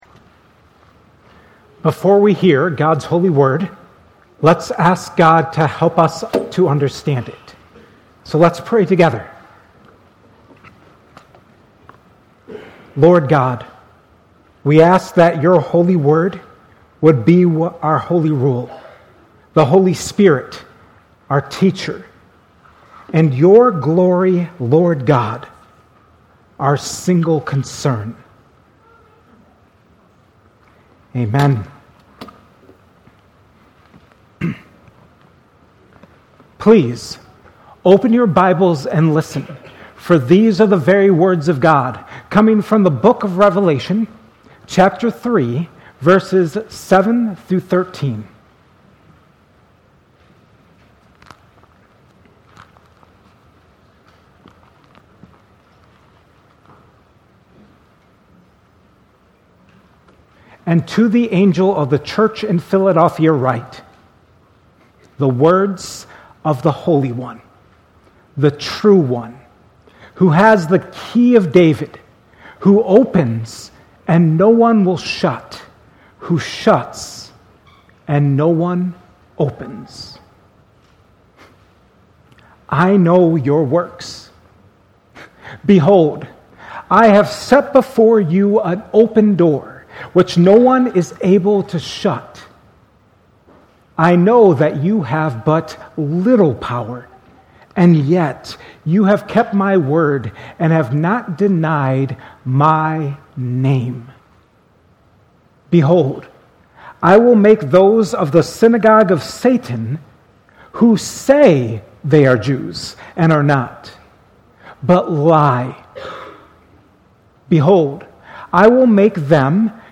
2026 at Cornerstone Church in Pella.